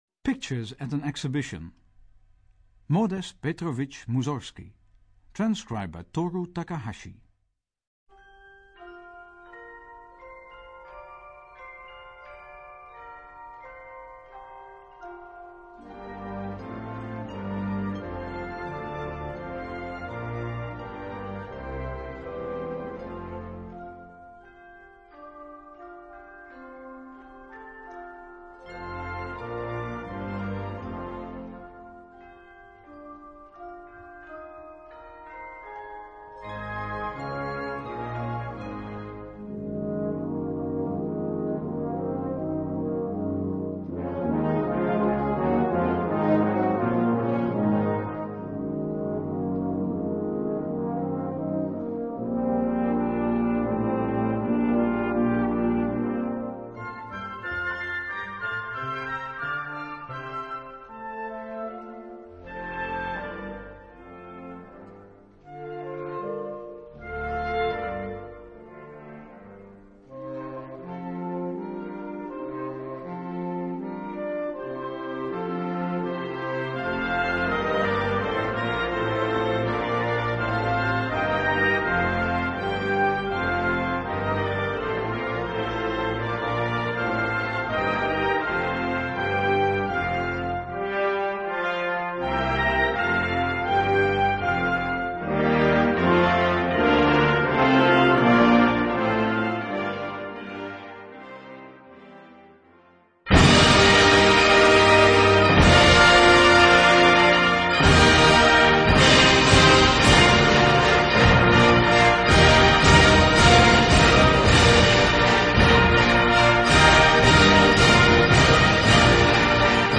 いずれの楽章も原調（B Major, G-sharp minor など難しい調を含みます）